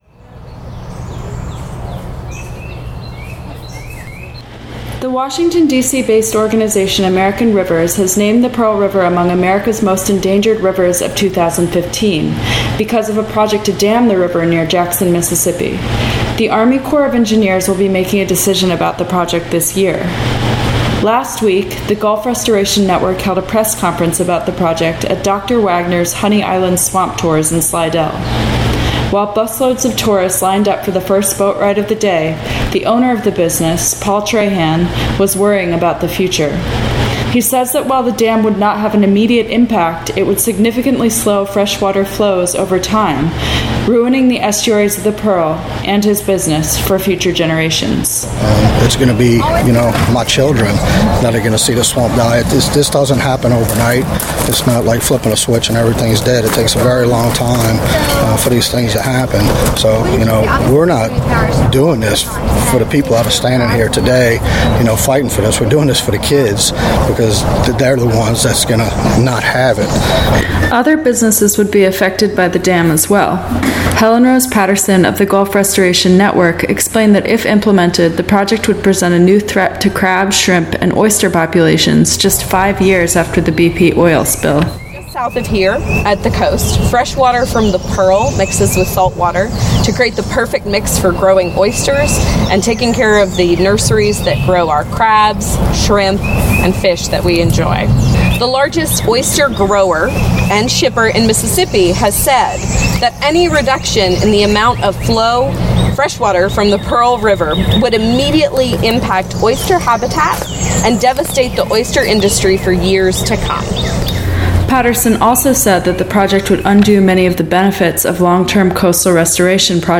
final_piece_pearl_river_press_conference.mp3